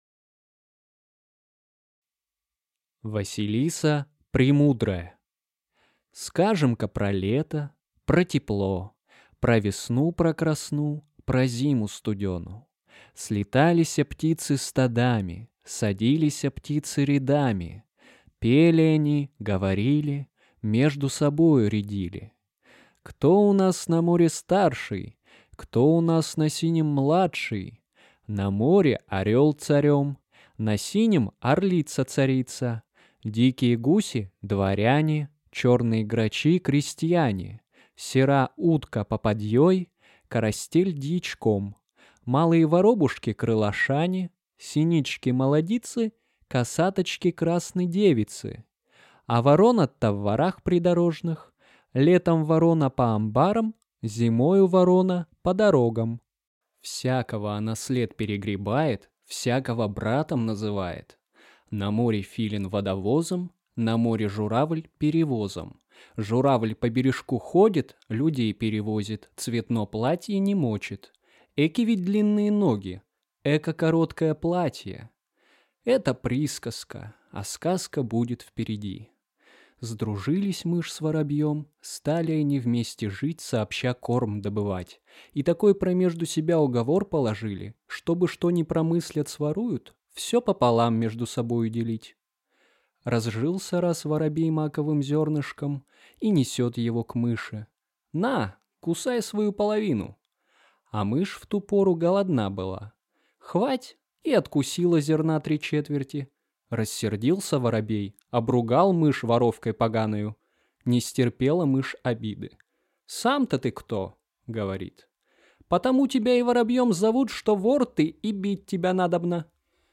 Аудиокнига Василиса Премудрая | Библиотека аудиокниг